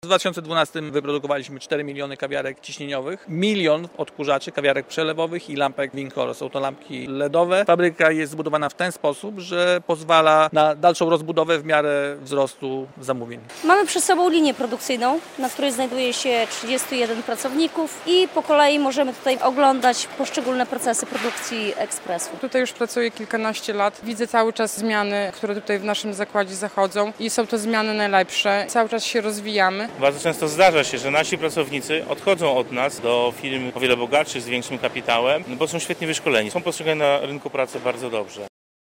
Nowa fabryka Biazetu w Białymstoku - relacja